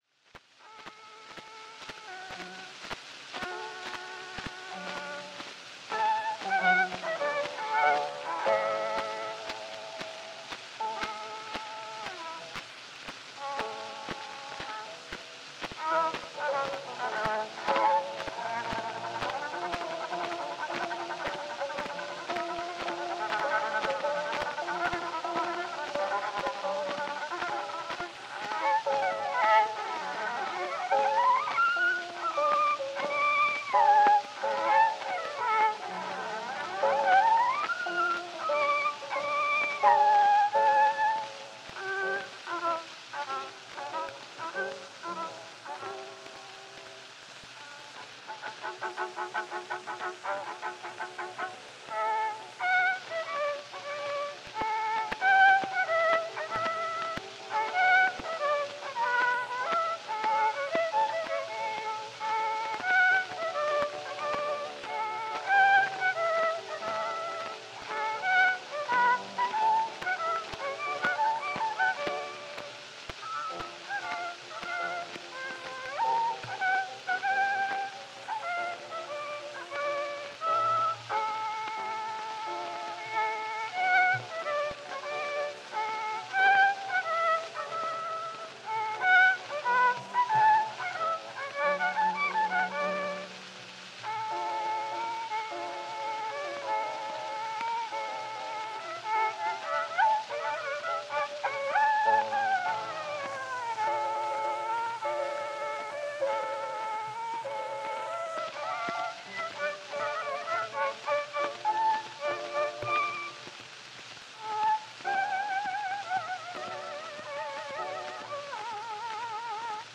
Fakat, British Libary’nin sistesinde yayımlanmış Sivori araştırmasını okursanız (araştırmanın birinci, ikinci ve üçüncü üçüncü bölümü) oldukça bilimsel yöntemlerle belli alternatifleri ve potansiyel sorunları çürüterek, silindir kayıt teknolojisinden tutun Sivori’nin bulunduğu lokasyonlara değin didik didik edilerek elde edilmiş birincil elden toplanan birçok dellile, neredeyse şüpheye yer bırakmadan ortaya konmuş bir araştırma sonucu var karşımızda.
Bu kayıtlarda Sivori’nin tekniği bugün anlaşıldığı biçimde “çok iyi” değil çünkü araştırmada da vurgulandığı üzere kayıtlar onun ölümünden çok çok kısa bir süre önce, oldukça yaşlı ve hasta bir durumdayken gerçekleştirilmiş.
Dolasıyla 80 yaşında ölümüne günler kalmış hasta birinin kayıt psikolojisi altında belli hatalar yapması anlaşılabilir, ki günümüzdeki gibi defalarca kayıt alınmadığını da kaydın tek seferde gerçekleştiğini belirtmek gerekir.
Bu bağlamda Sivori kemanda verdiği ses efektleri gerçekten de eserin programına uygun yaşlı bir kadının sahip olacağı ses tonuna yakınsıyor, tüm merkez kemandan elde edilen farklı ses efektleri var. Burada yaşlı bir kadın (cadı) şarkı söylüyormuşcasına bir çeşit “bilinçli bozukluk” var, bu hem biraz ürkünç/tuhaf hem de mizahi bir karakter sağlıyor.